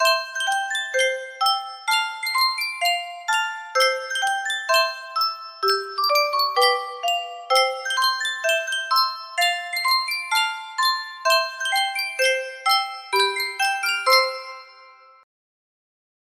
Sankyo Music Box - Bringing in the Sheaves Cper music box melody
Full range 60